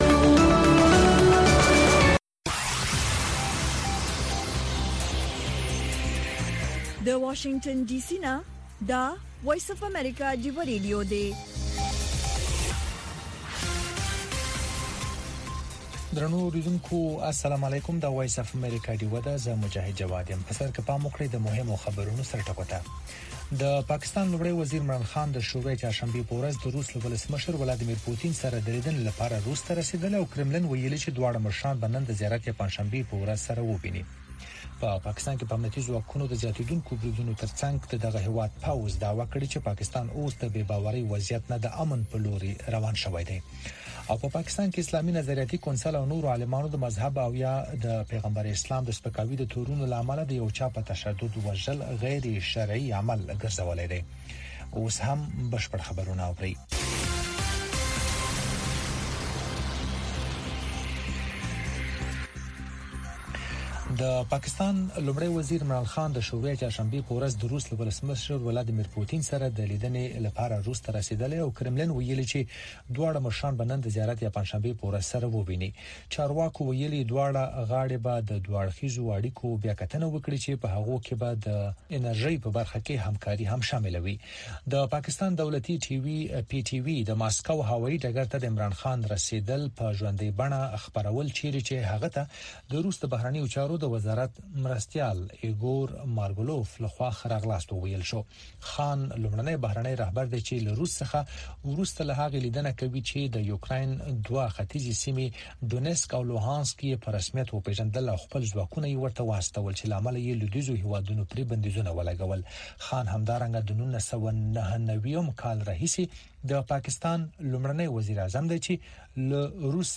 د وی او اې ډيوه راډيو سهرنې خبرونه چالان کړئ اؤ د ورځې دمهمو تازه خبرونو سرليکونه واورئ.